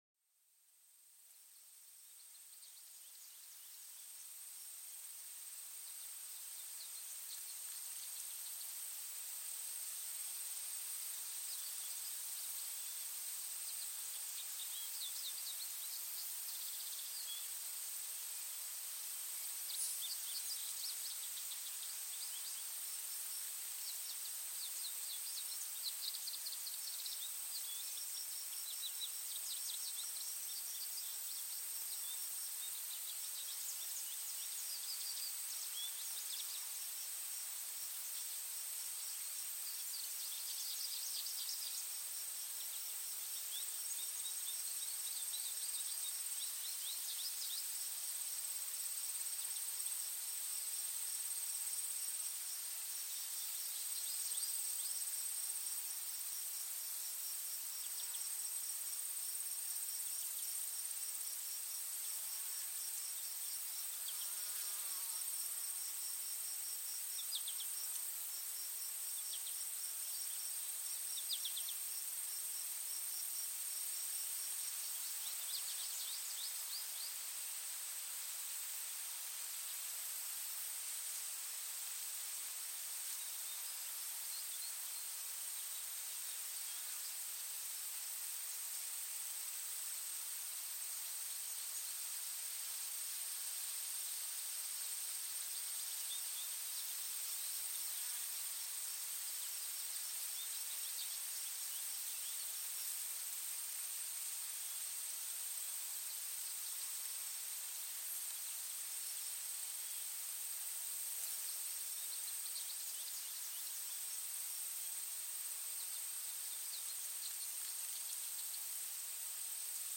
Sumérgete en el corazón de un bosque matutino, donde el canto de los pájaros te envuelve en una melodía relajante. Déjate llevar por estas armonías naturales que invitan a la relajación y la meditación.